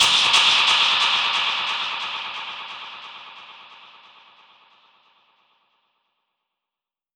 Index of /musicradar/dub-percussion-samples/134bpm
DPFX_PercHit_C_134-04.wav